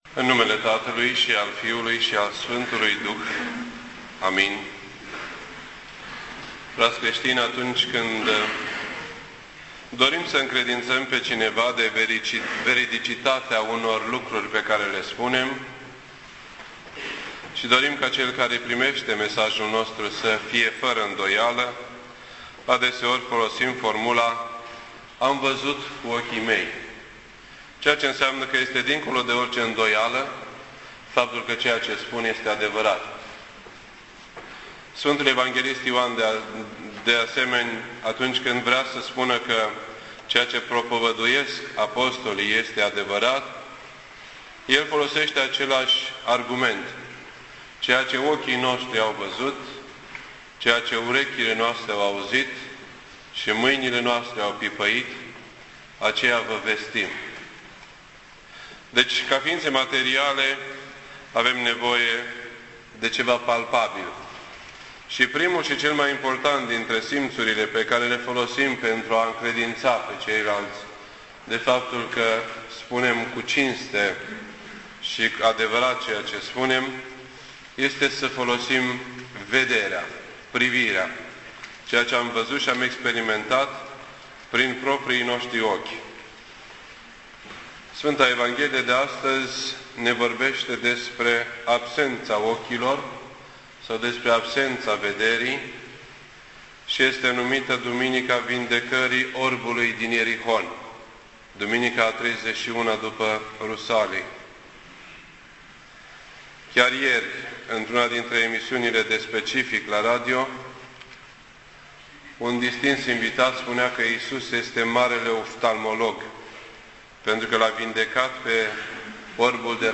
This entry was posted on Sunday, January 16th, 2011 at 8:58 PM and is filed under Predici ortodoxe in format audio.